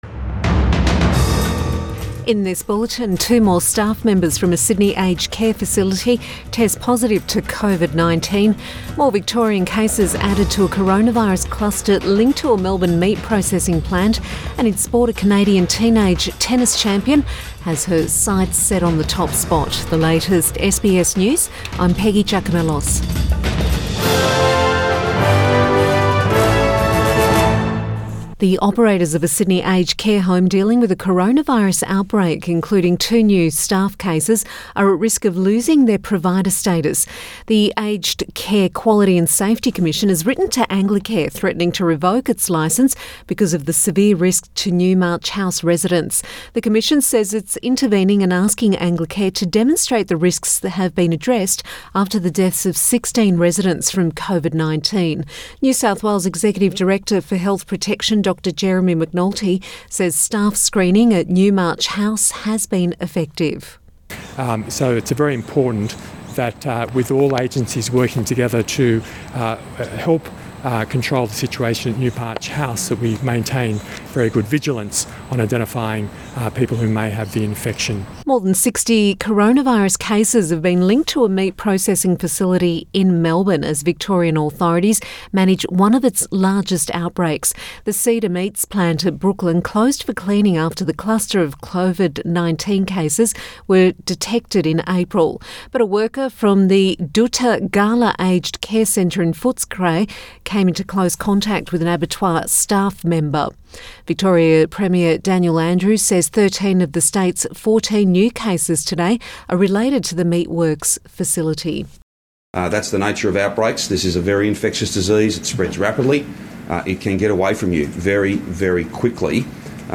Midday bulletin May 7 2020